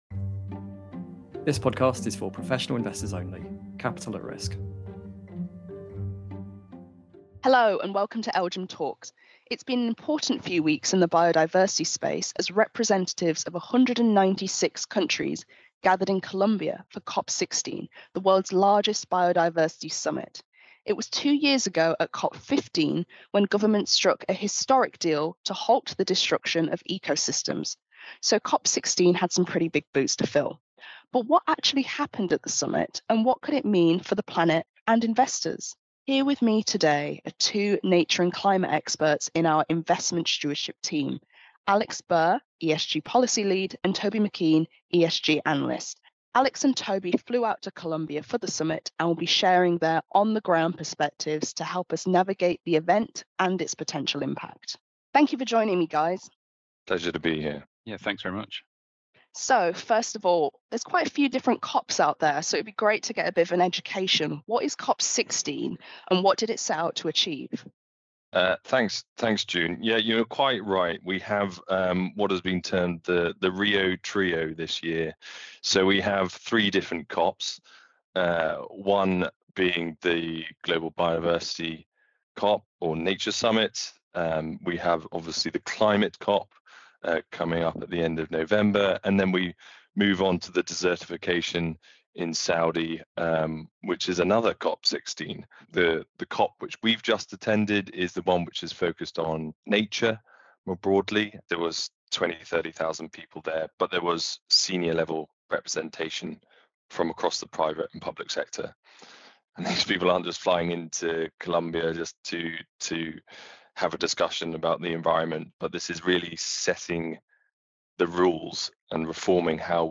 But what actually happened at the summit and what could it mean for the planet and investors? In this episode, we interview two climate and nature experts in our Investment Stewardship team